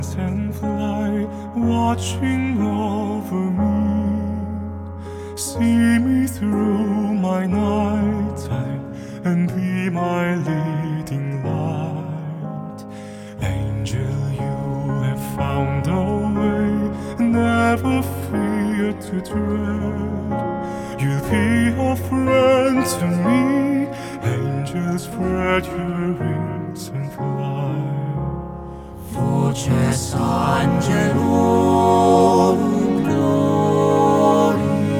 Жанр: Классика